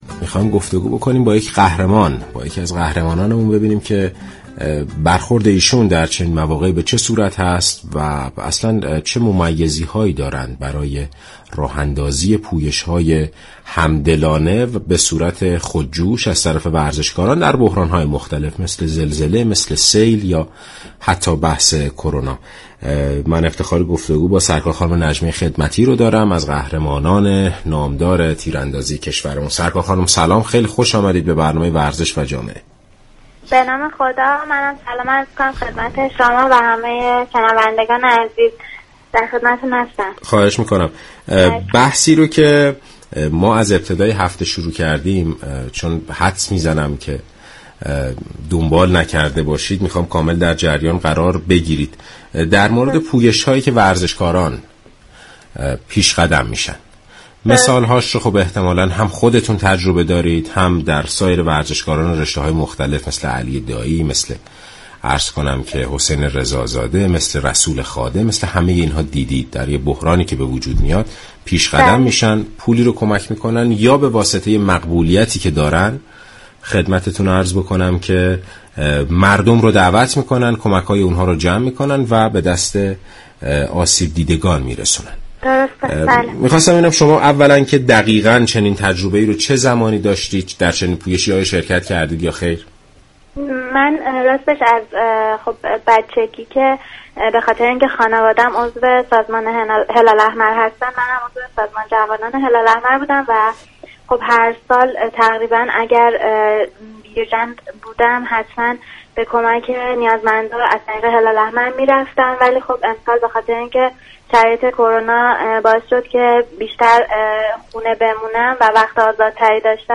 برنامه ورزش و جامعه دوشنبه 12 خرداد با حضور نجمه خدمتی، قهرمان جهانی تیراندازی كشورمان به موضوع تلاش وی برای انجام كمك های مومنانه و خداپسندانه برای یاری نیازمندان پرداخت.